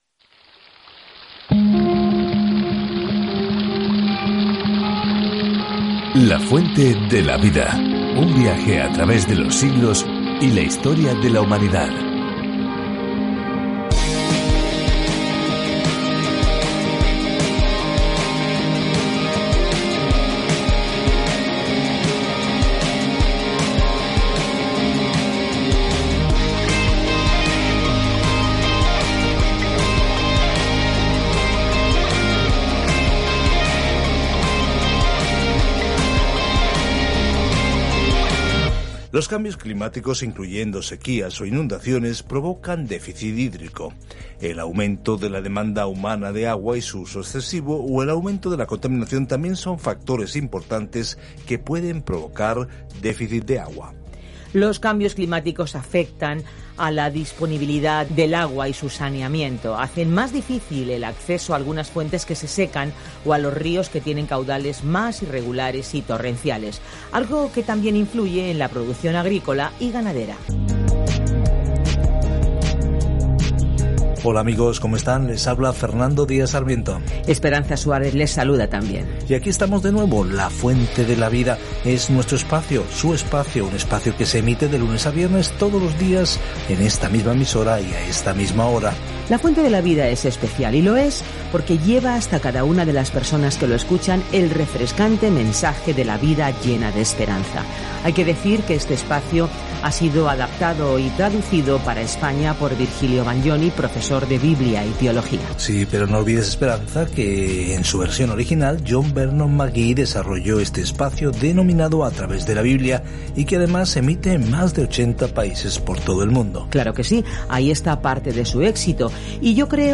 Scripture 1 Peter 4:1-4 Day 11 Start this Plan Day 13 About this Plan Si estás sufriendo por Jesús, entonces esta primera carta de Pedro te anima a seguir los pasos de Jesús, quien sufrió por nosotros primero. Viaja diariamente a través de 1 Pedro mientras escuchas el estudio en audio y lees versículos seleccionados de la palabra de Dios.